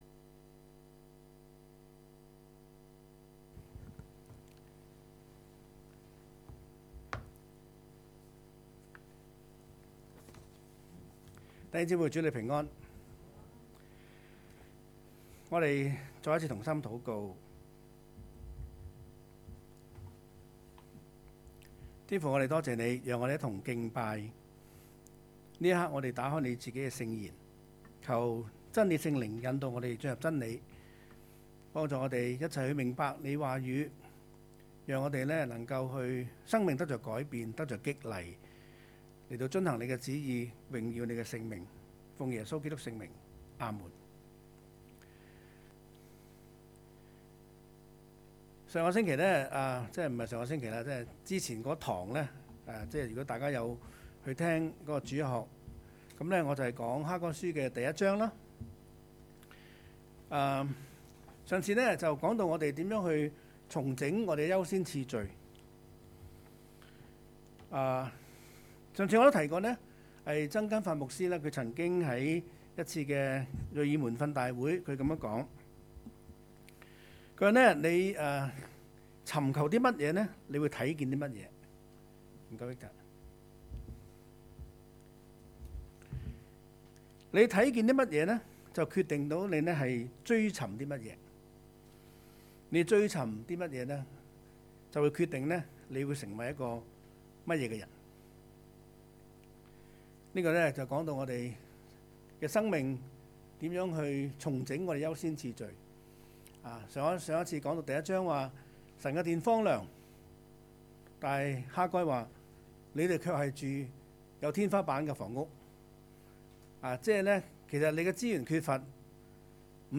2020年9月20崇拜
講道 :欠缺還是夠